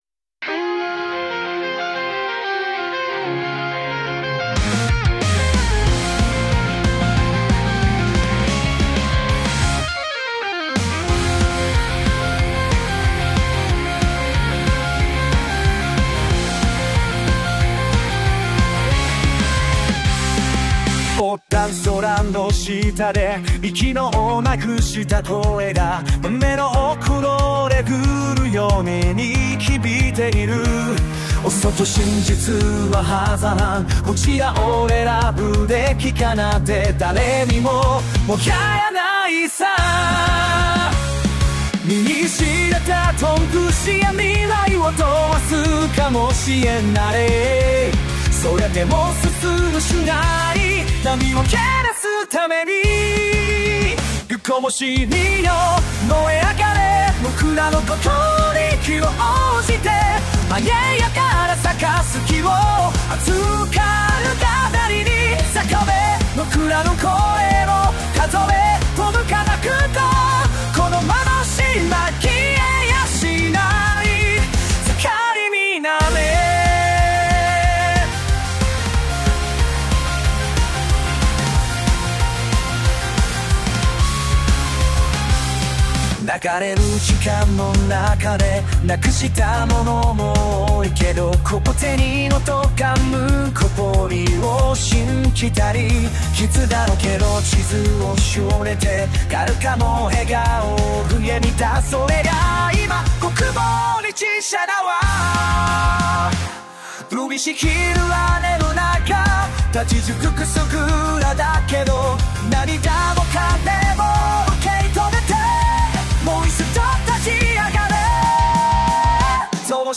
プロンプト通り、邦楽ロックが生成されました。
音楽の質は高いと思いますが、日本語に少し違和感があり、SUNOの方が質が良いです。